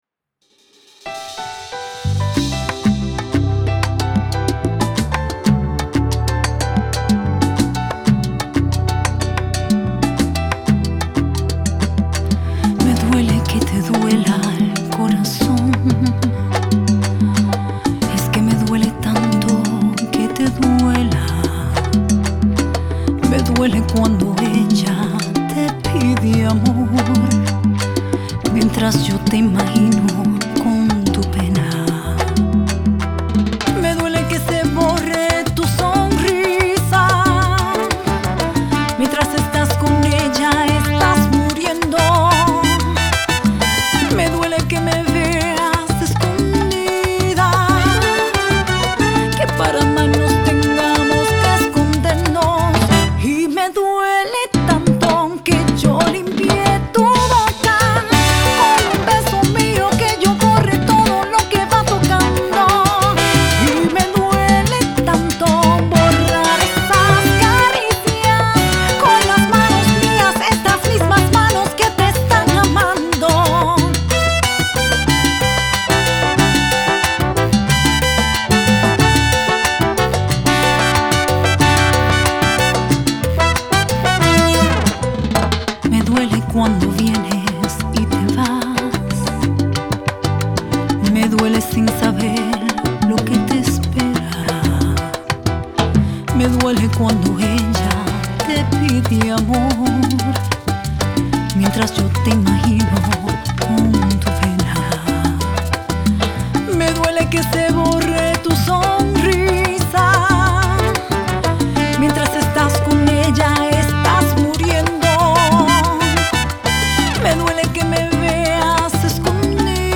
una emotiva canción